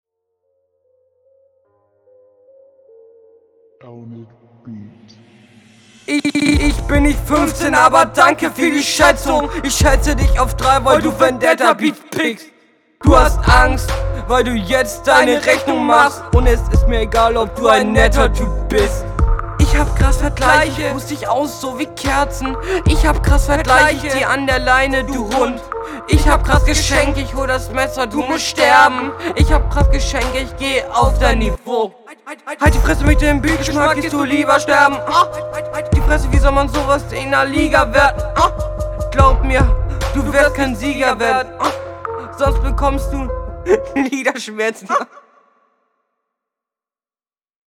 Oh Junge die Doubles Clippen ja ultra, das klingt ja mega unangenehm, mach mal den …
dein mic is anscheinend nicht das geilste aber deswegen zieh ich dir keinen punkt ab.